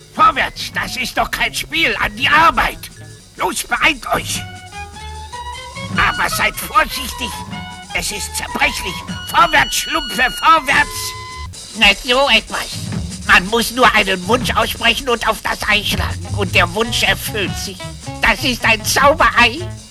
Großer (Papa) Schlumpf: